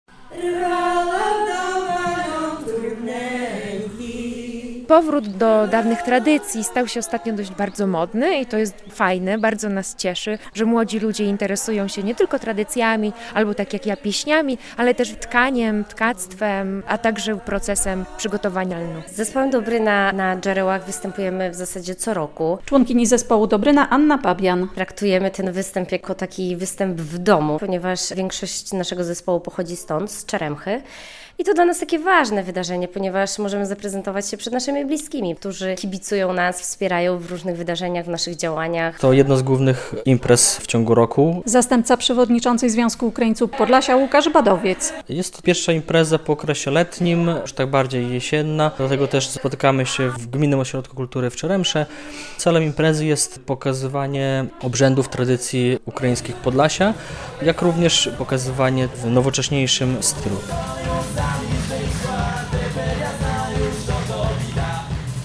"Dżereła" w Czeremsze - relacja
W niedzielę (28.09) w Gminnym Ośrodku Kultury w Czeremsze odbyły się "Dżereła – ukraińskie tradycje i transformacje”.